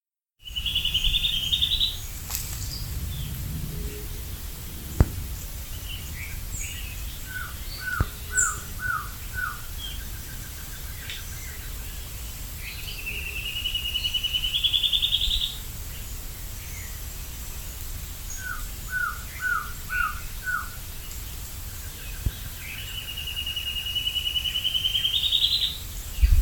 Rufous Gnateater (Conopophaga lineata)
Life Stage: Adult
Location or protected area: Reserva Privada y Ecolodge Surucuá
Condition: Wild
Certainty: Recorded vocal